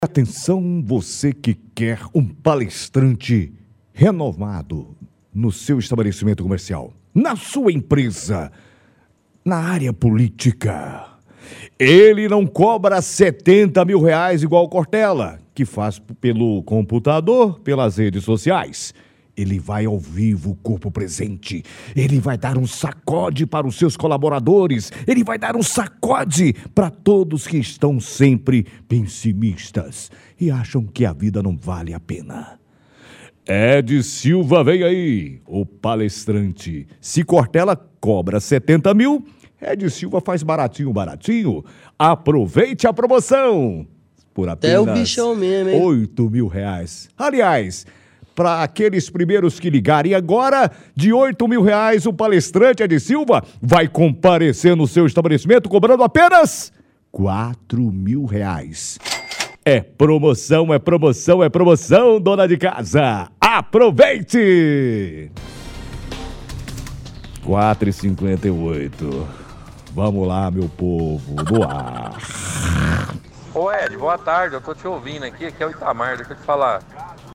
-Ouvinte: Ironiza em relação ao valor pago pela Prefeitura ao palestrante e diz que agora quer virar palestrante.
Faz deboche com voz de propaganda dizendo que se o Cortella cobra R$ 70 mil, ele cobra apenas R$ 4 mil.